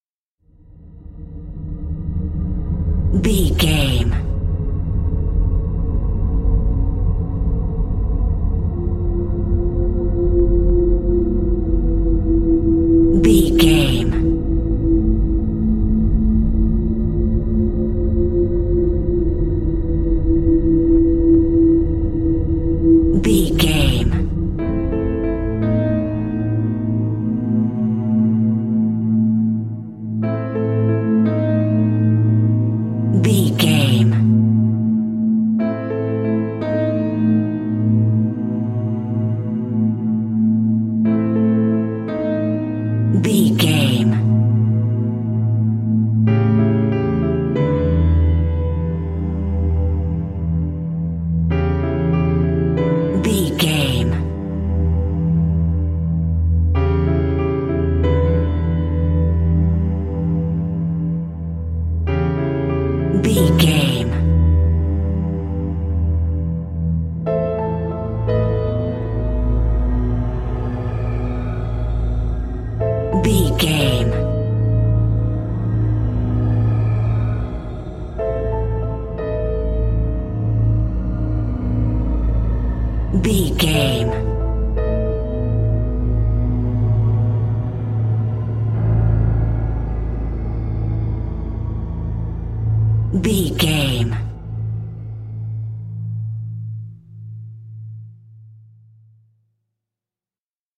Thriller
Aeolian/Minor
Slow
piano
synthesiser
electric piano
tension
ominous
dark
suspense
haunting
creepy